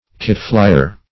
Kiteflier \Kite"fli`er\
kiteflier.mp3